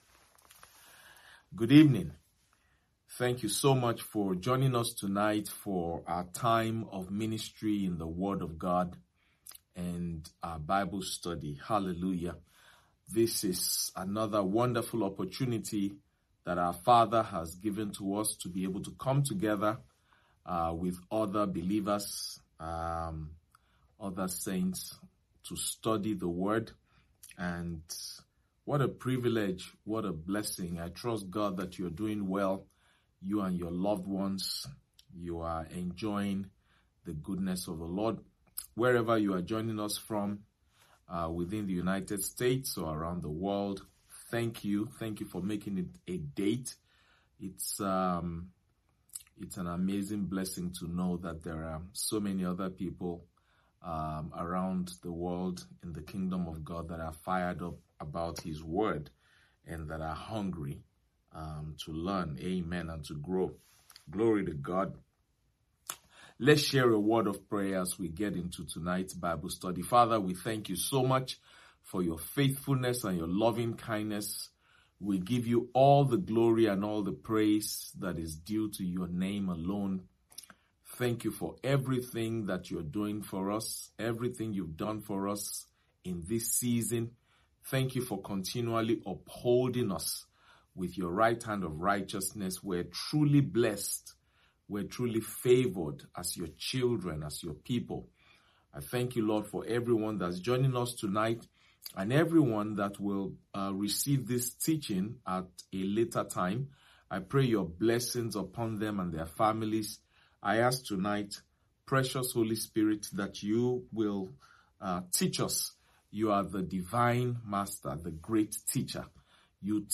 Midweek Service